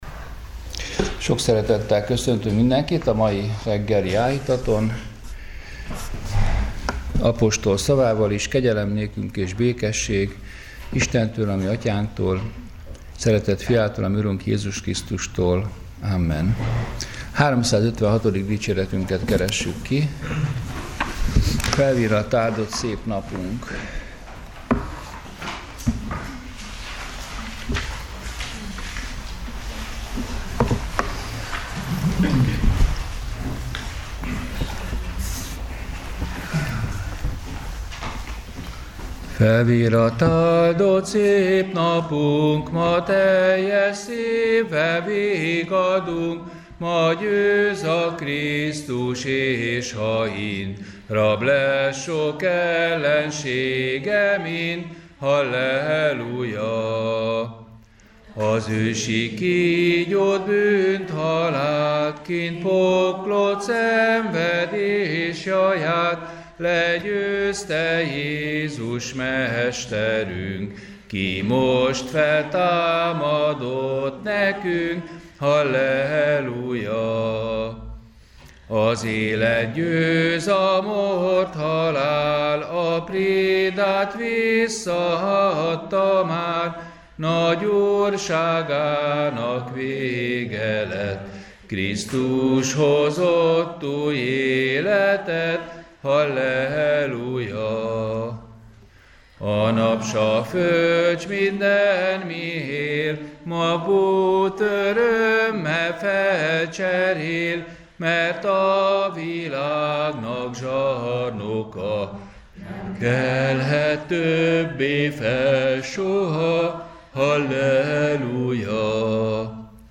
Áhítat, 2019. április 24.